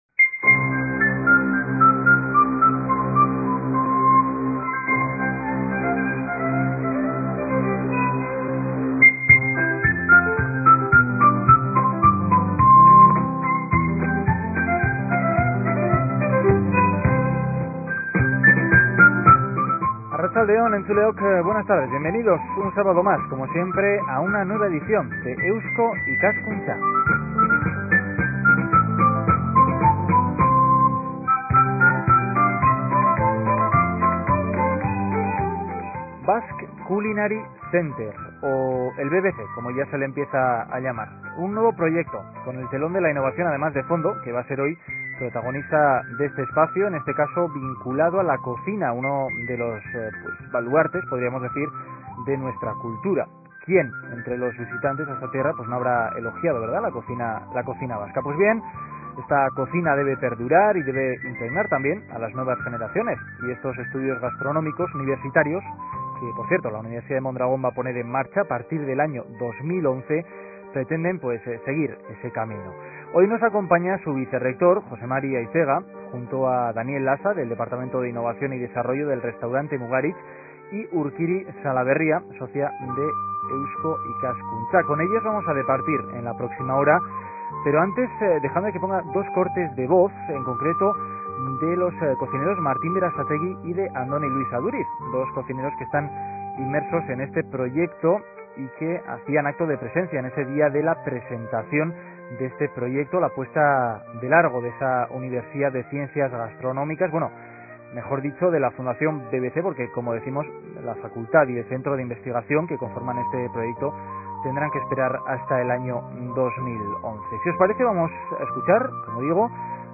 Descargar audio Basque Culinary Center En la presente tertulia conoceremos el proyecto Basque Culinary Center, que se construirá en breve en el País Vasco Fondo Eusko Ikaskuntza Número registro 000966.mp3 Compartir